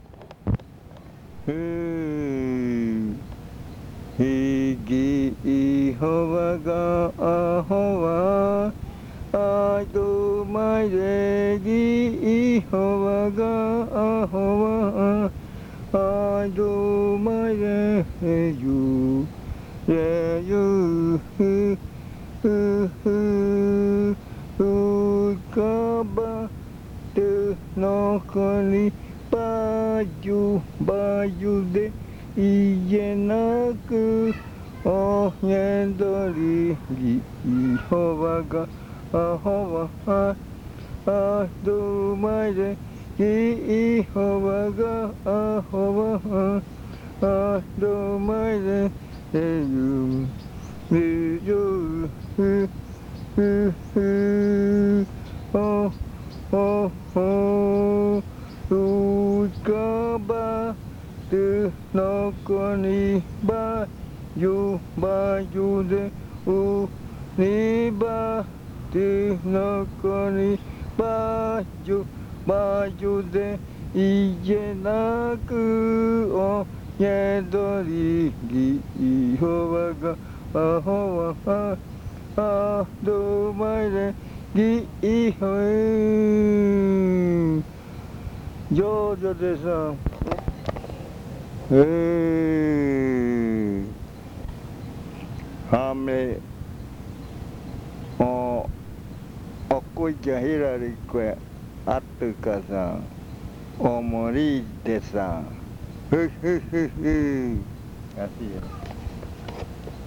Leticia, Amazonas
Canto muruikɨ
Muruikɨ chant